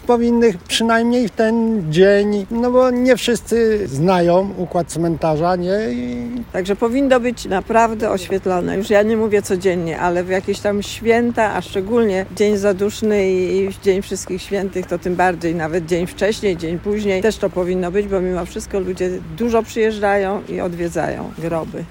– Szczególnie w okolicach Wszystkich Świętych lampy powinny być sprawne – mówią zielonogórzanie: